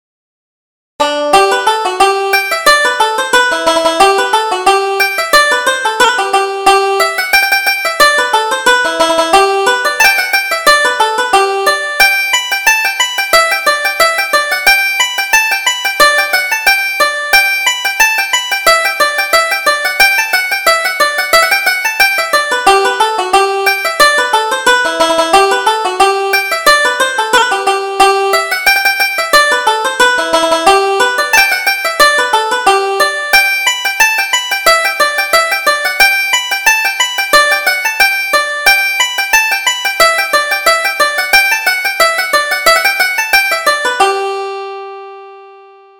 Reel: Captain Kelly's Reel